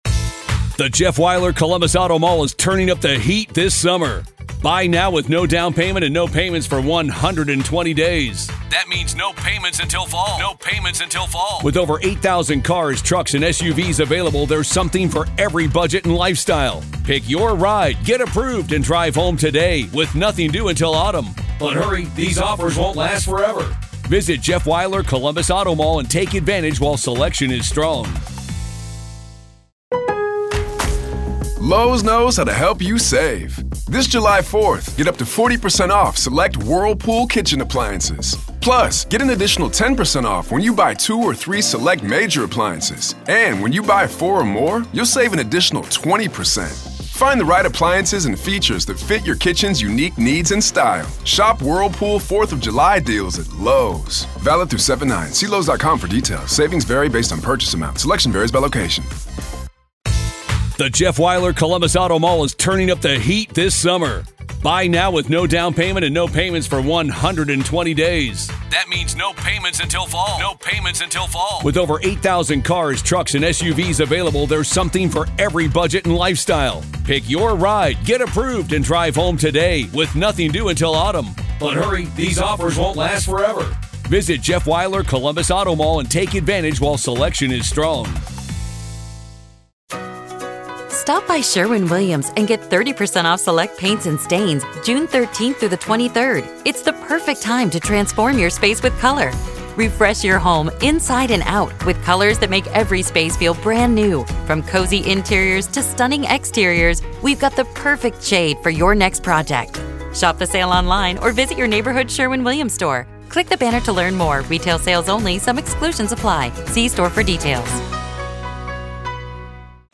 This is audio from the courtroom in the high-profile murder retrial of Karen Read in Dedham, Massachusetts.